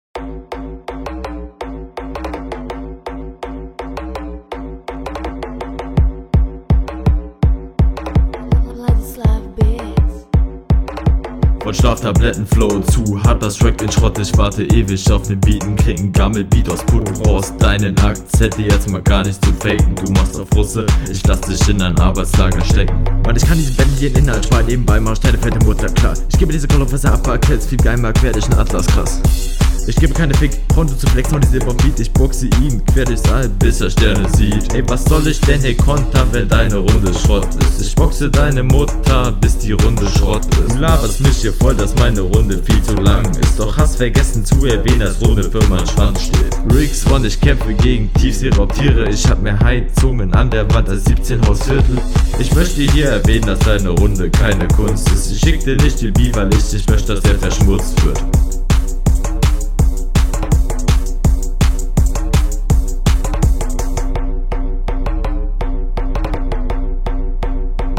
Die Flowansetzungen sind gut nur kommen nicht so druckvoll durch wegen des Mixing und du …